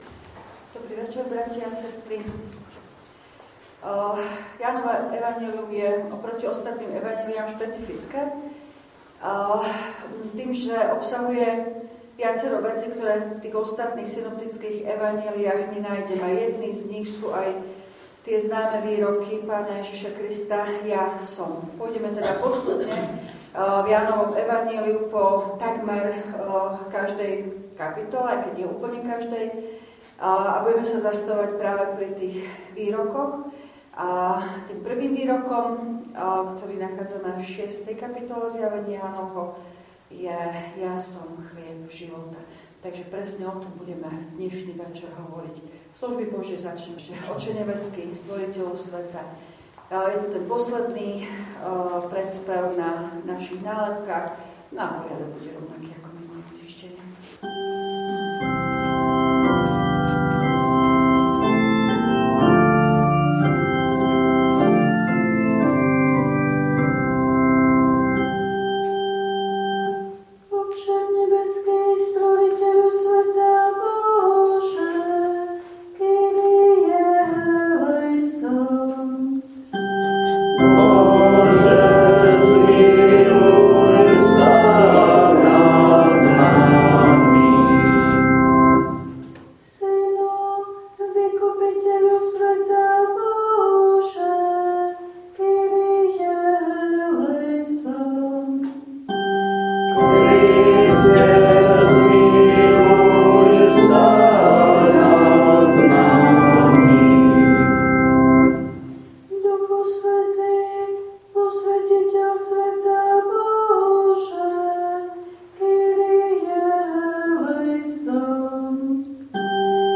Pôstne večerné služby Božie_19_3_2025
V nasledovnom článku si môžete vypočuť zvukový záznam z večerných pôstnych služieb Božích_19_3_2025.